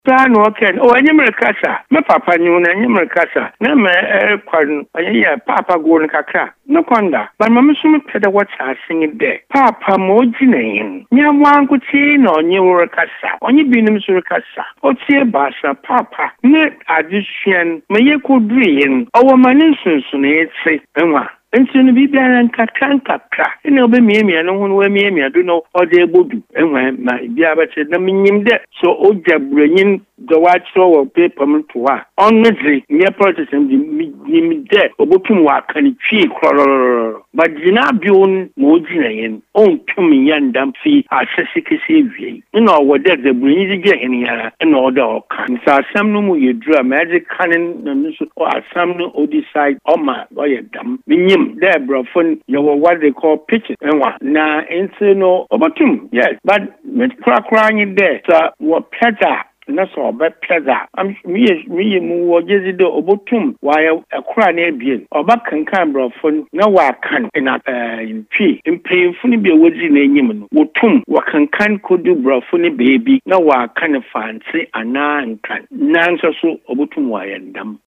Listen to the professor